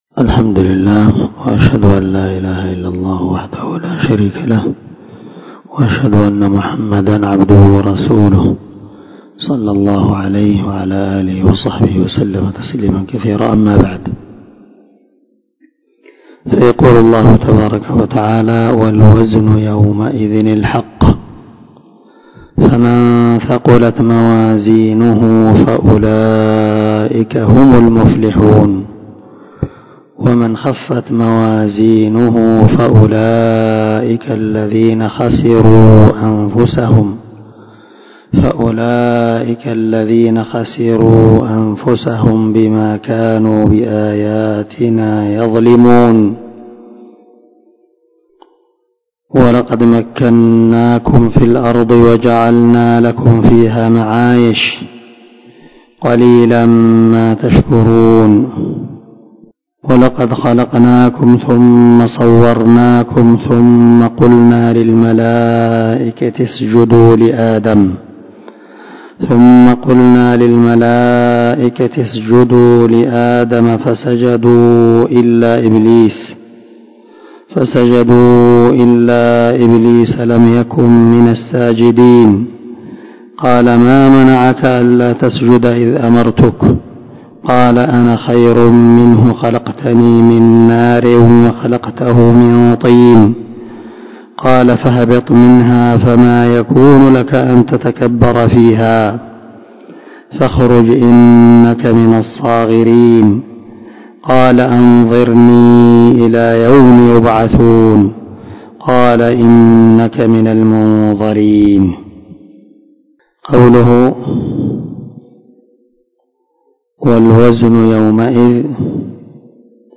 450الدرس 2 تفسير آية ( 8 – 15 ) من سورة الأعراف من تفسير القران الكريم مع قراءة لتفسير السعدي
دار الحديث- المَحاوِلة- الصبيحة.